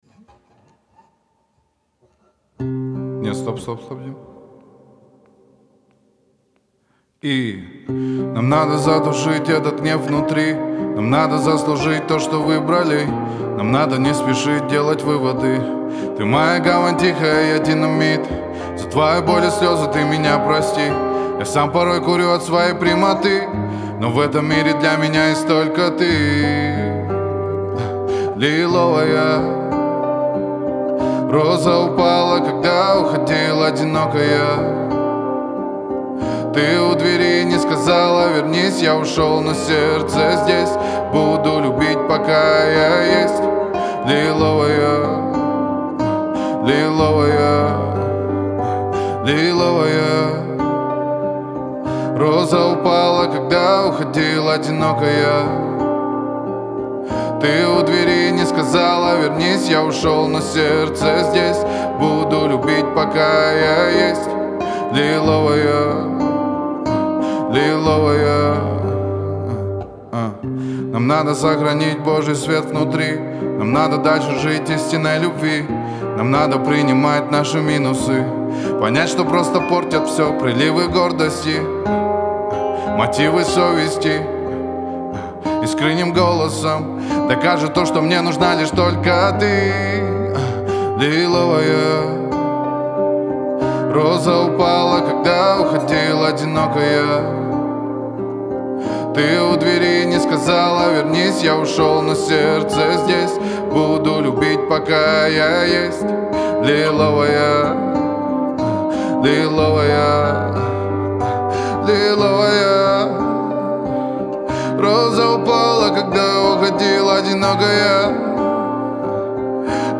это трек в жанре акустического хип-хопа